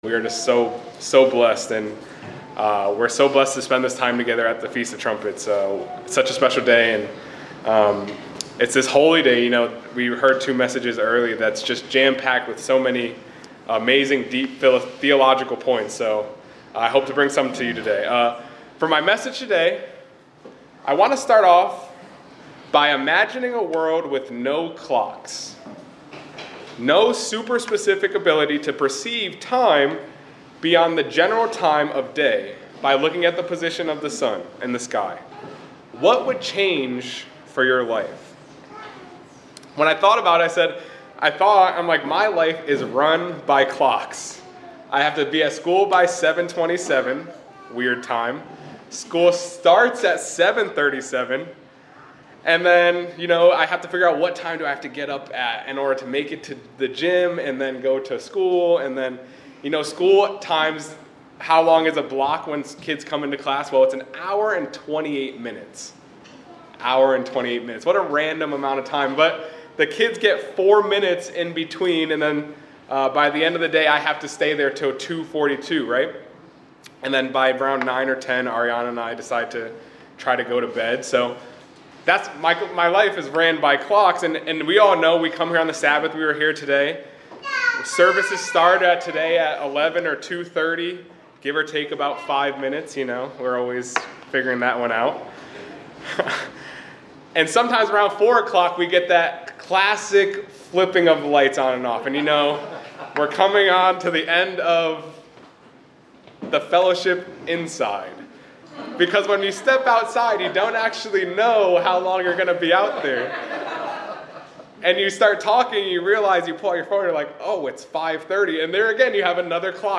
The sermon emphasizes the importance of The Feast of Trumpets and the concept of remembrance in the Bible. The speaker discusses how the trumpets served as reminders for significant events and holy days, and explores the theological implications of the Hebrew word "zikaron" (remembrance).